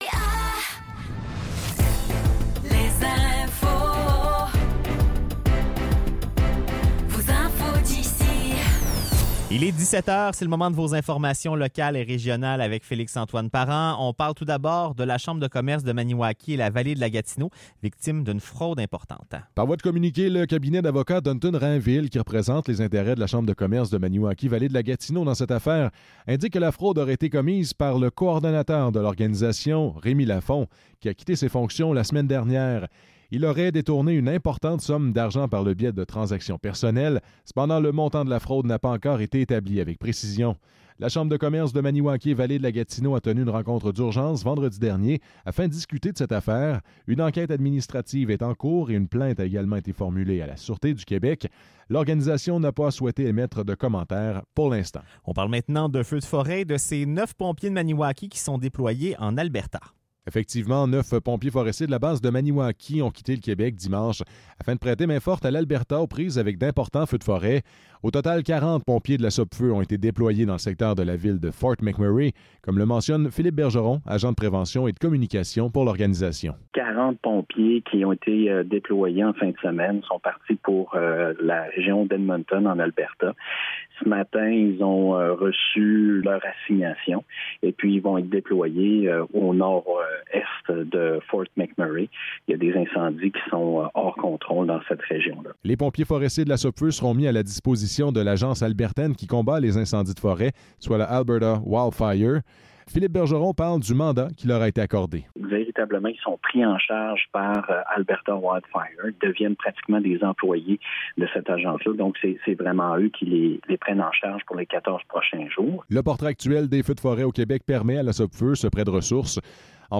Nouvelles locales - 29 juillet 2024 - 17 h